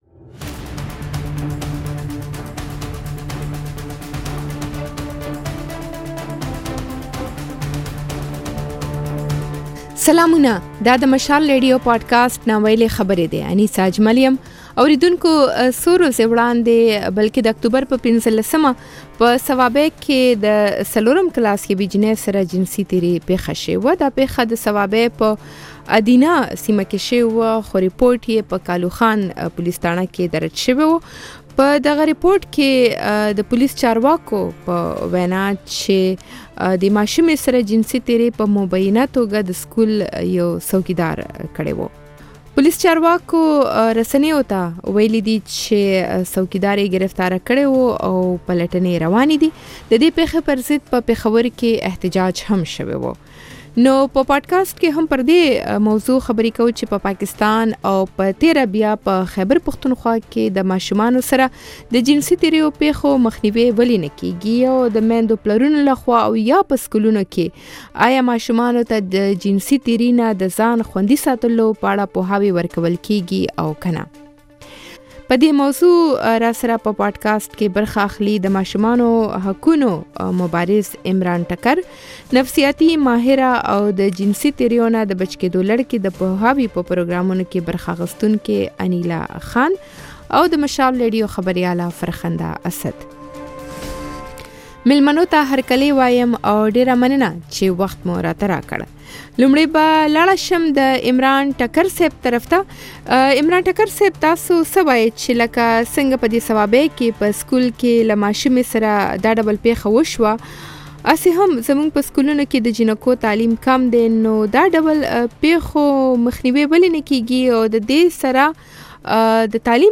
د مشال راډیو په پاډکاسټ "نا ویلي خبرې" کې مو دا ځل پر ماشومانو د جنسي تشدد په اړه بحث کړی دی. پر دې هم غږېدلي یو چې په پاکستان، او په تېره په خیبر پښتونخوا کې، پر ماشومانو د جنسي تېریو د پېښو مخه ولې نه نیول کېږي او په دې لړ کې د ماشومانو پوهاوی څومره اړین دی.